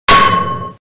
Download Half Life Crowbar Clang sound effect for free.
Half Life Crowbar Clang